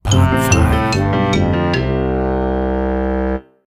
Game_Over_1.mp3